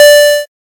safe-5.ogg.mp3